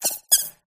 grookey_ambient.ogg